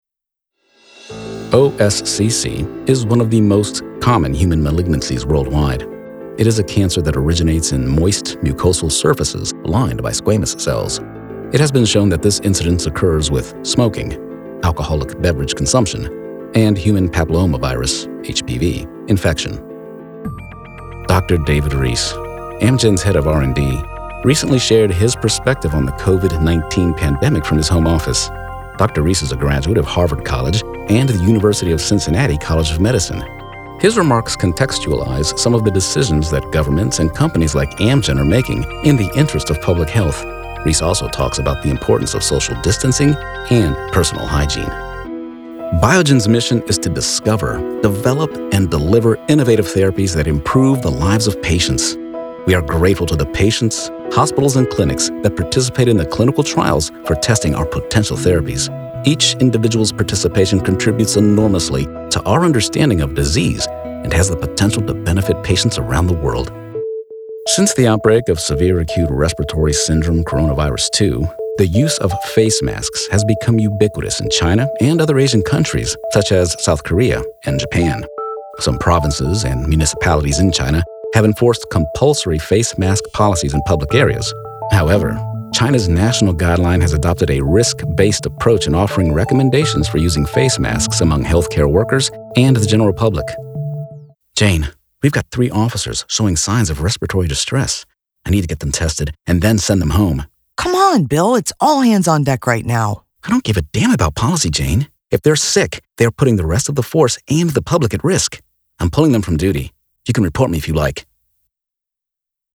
Medical Narration Demo
English (North American)